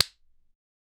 body slap, like two moist bodies slapping together 0:01 Created Mar 13, 2025 8:43 AM looping fast body slaps with Hospital ambience until an unusual loud body slap sound was heard and woman gasped.
body-slap-like-two-moist-fqrpivkj.wav